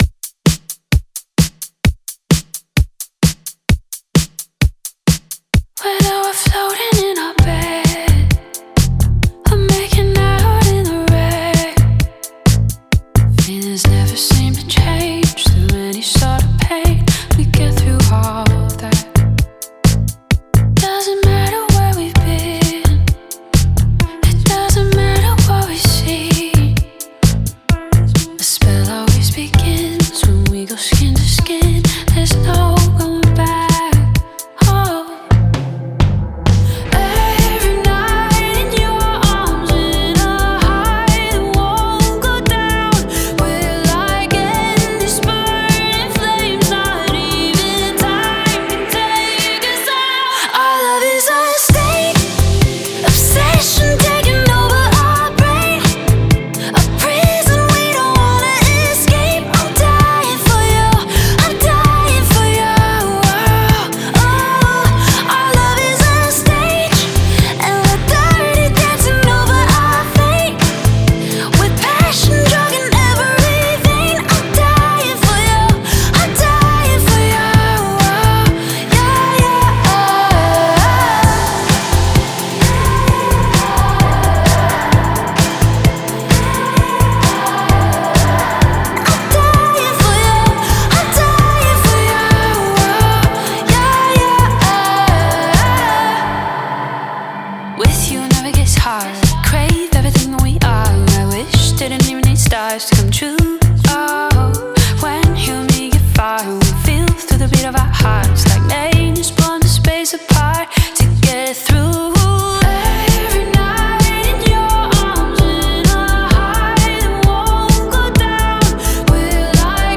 энергичная поп-песня
канадской певицы